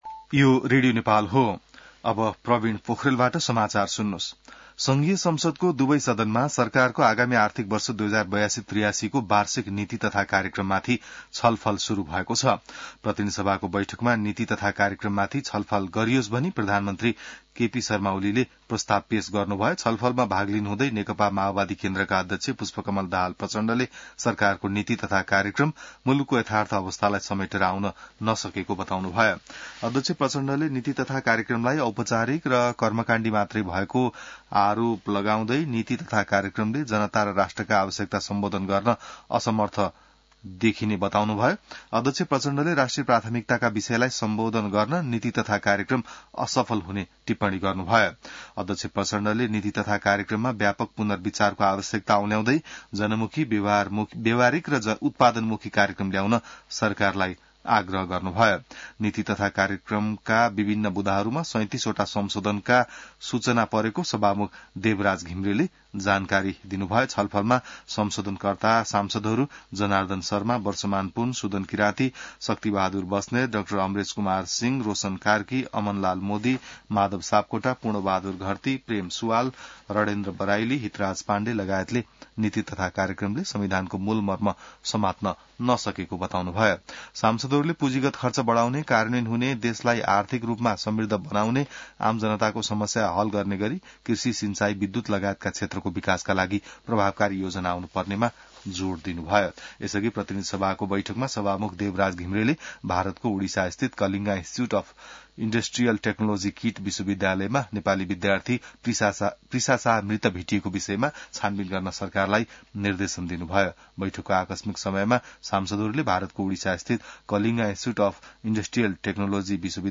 बिहान ६ बजेको नेपाली समाचार : २३ वैशाख , २०८२